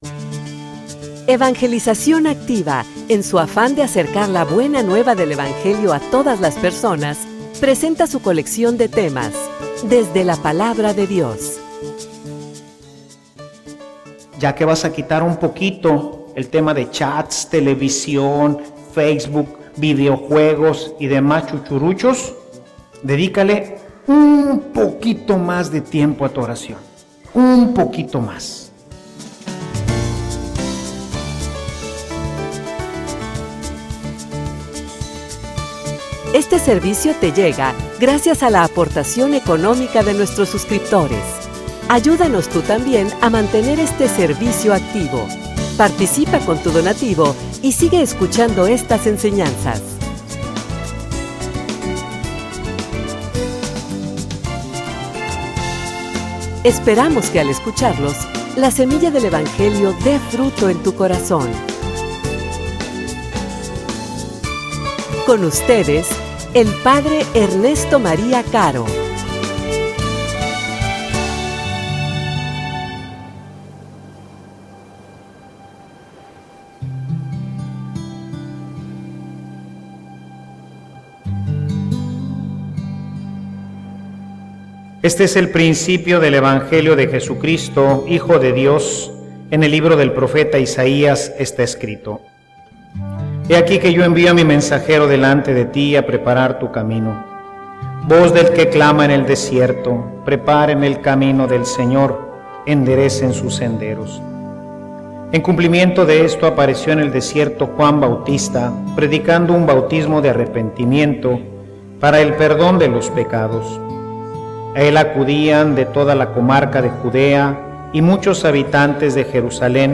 homilia_Un_tiempo_para_cambiar.mp3